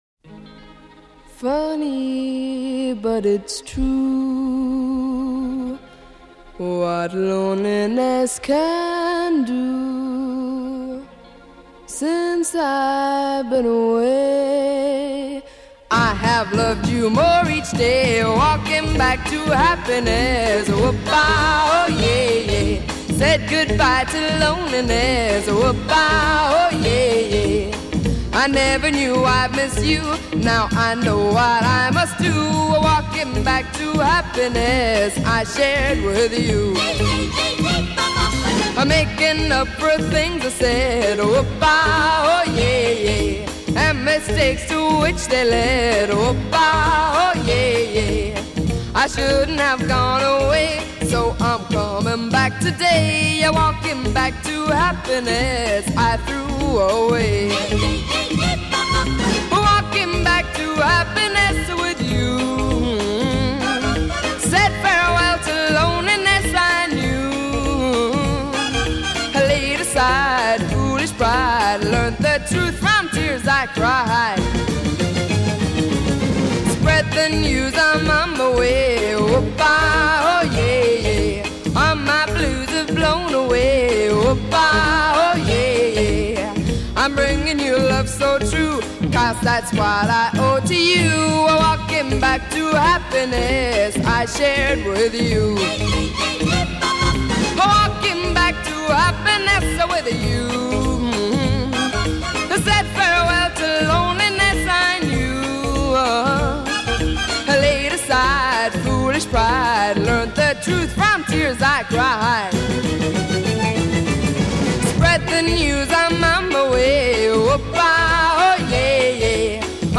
Verse 17 1:55 Transposition up a step e
Coda 9+ 2:16 Variation on chorus, fades out